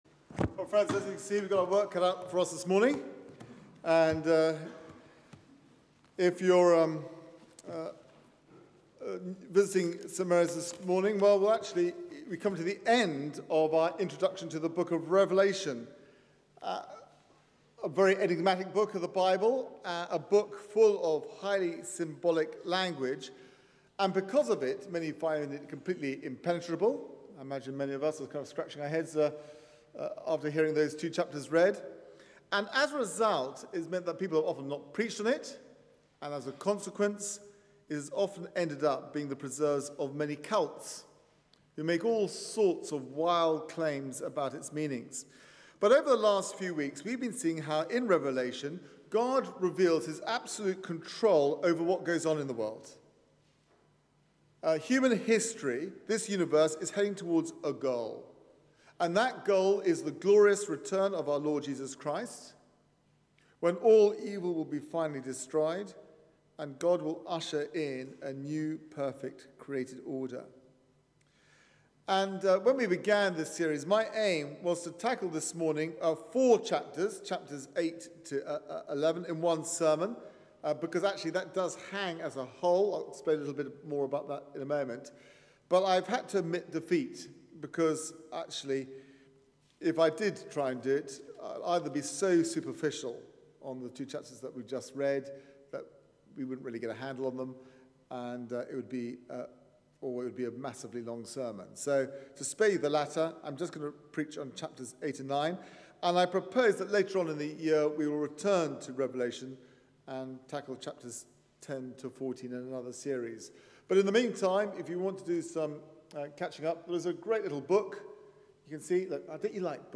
Media for 9:15am Service on Sun 05th May 2013 11:00 Speaker
Sermon